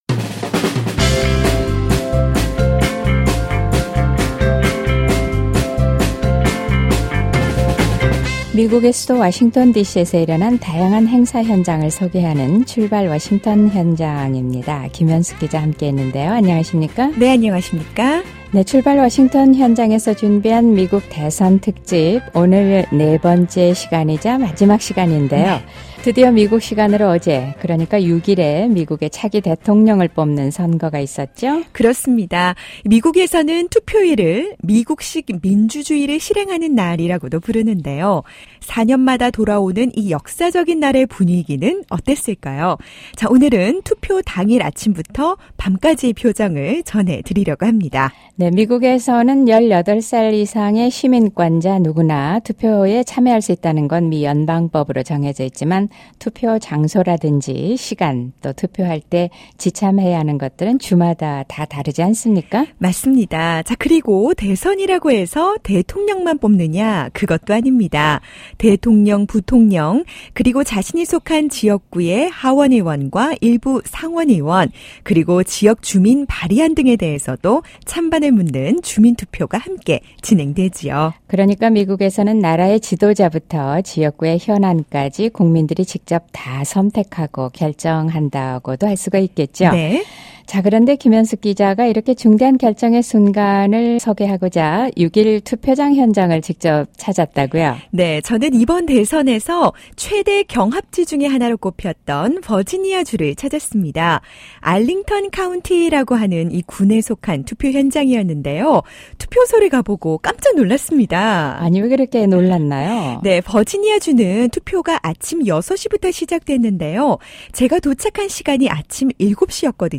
투표장 현장을 직접 찾아 유권자들의 목소리를 들어보고, 또 당선자 확정까지 긴장감 넘치는 순간들을 만나봅니다.